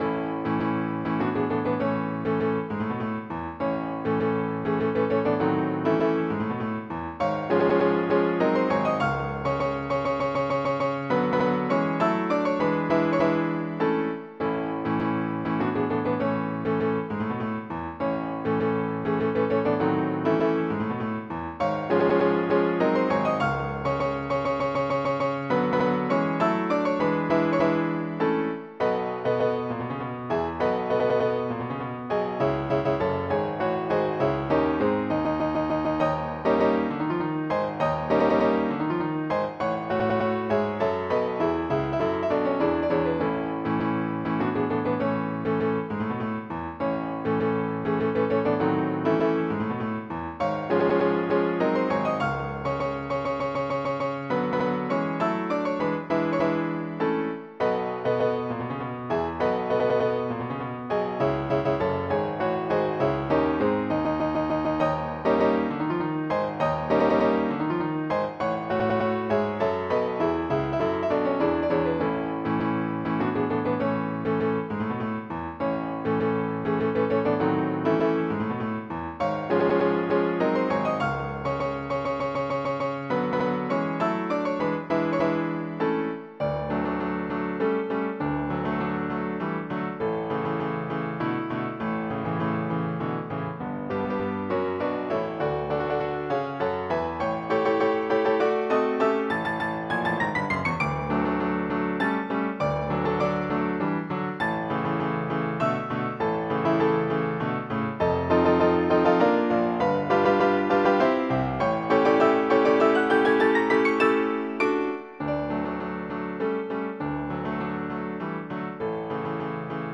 classica
MIDI Music File
Type General MIDI